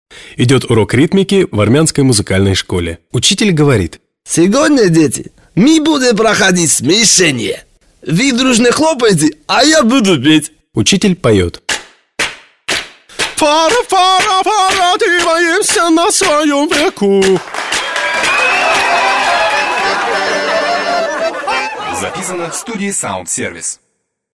Сотрудники студии звукозаписи «СаундСервис» в честь праздника создали и записали несколько приколов и шуток.